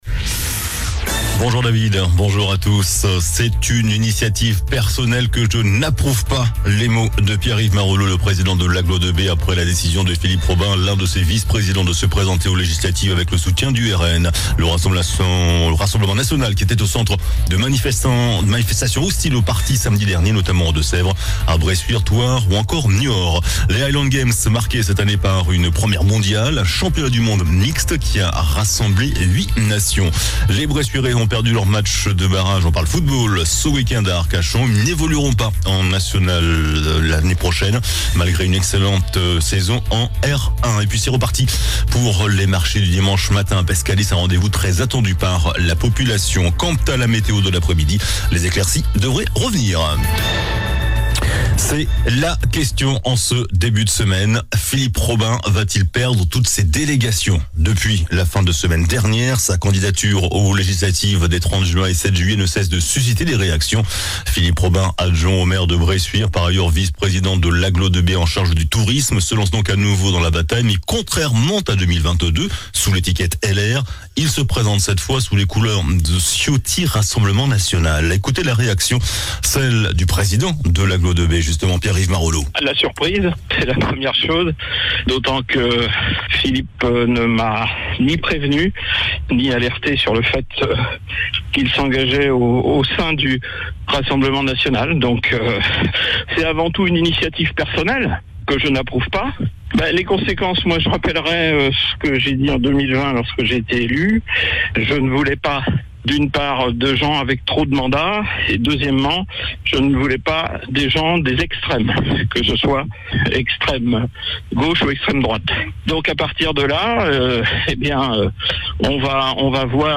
JOURNAL DU LUNDI 17 JUIN ( MIDi )